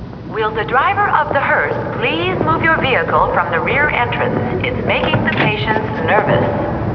hearse.wav